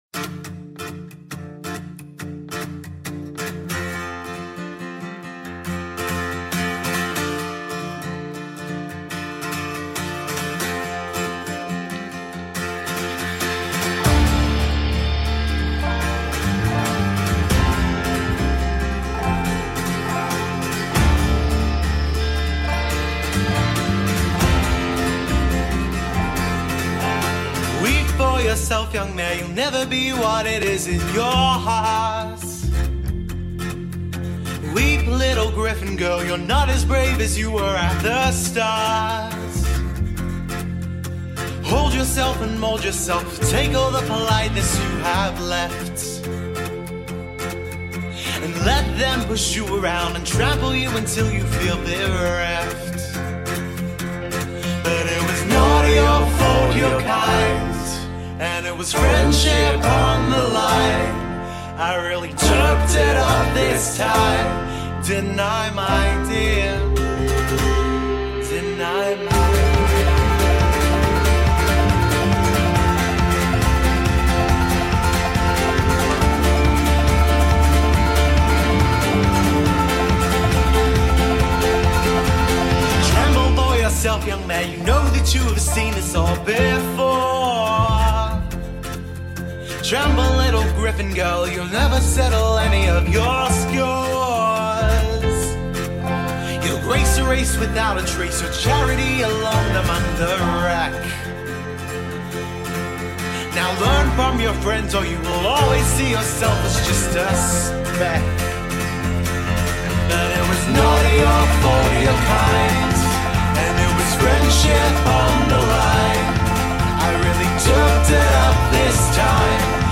parody/cover
The instrumental belongs to them.